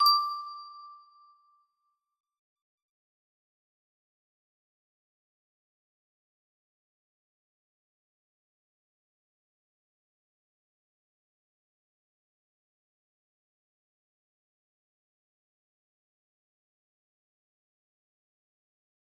bell music box melody